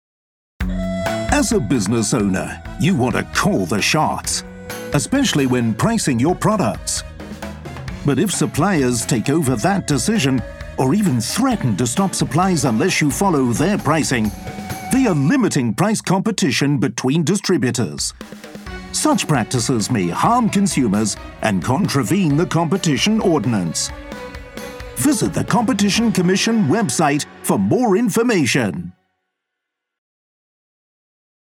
Radio Advertisement Click to view video